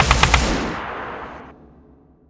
Gun2.wav